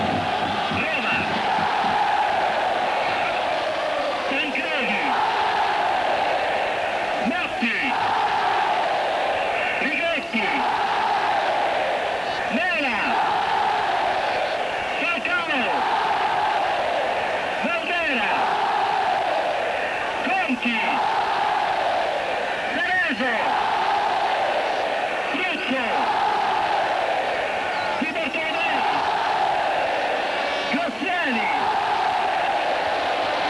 Semifinale Coppa dei Campioni